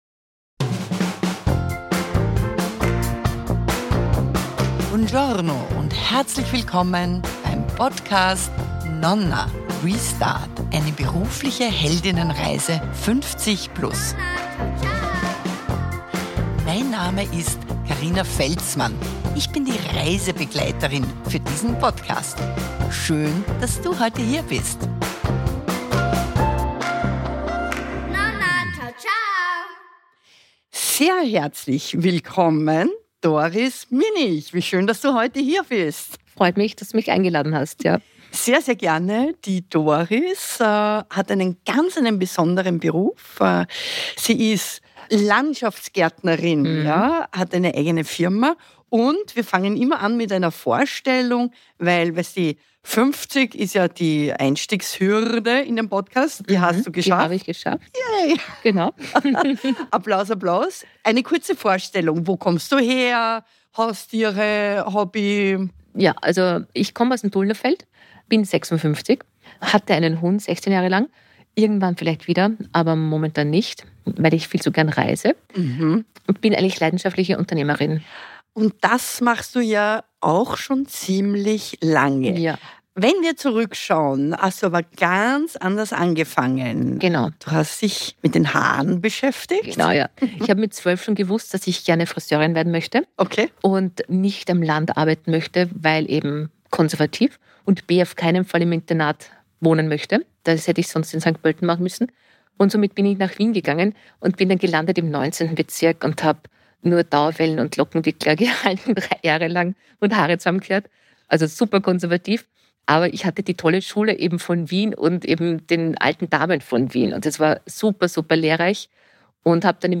interviewt zur beruflichen Held*innenreise